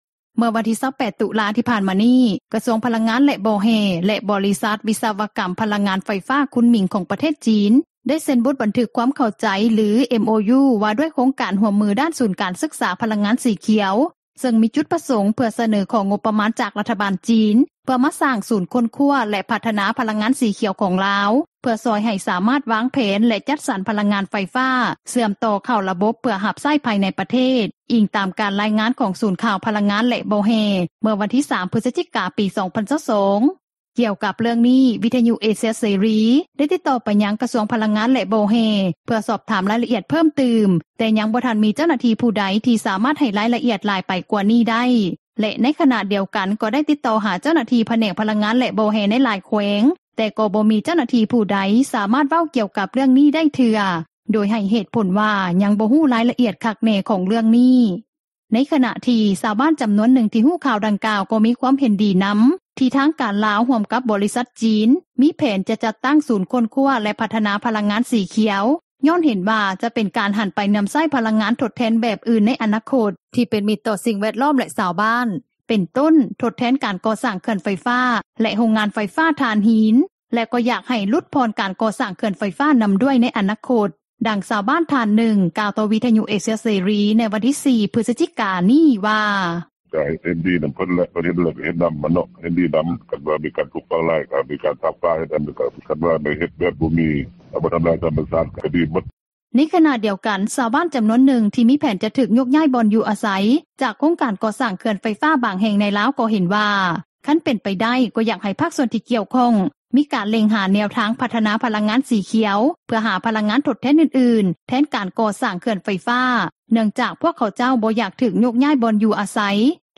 ດັ່ງຊາວບ້ານທ່ານນຶ່ງ ກ່າວຕໍ່ວິທຍຸ ເອເຊຽເສຣີ ໃນມື້ວັນທີ 4 ພຶສຈິກາ ນີ້ວ່າ: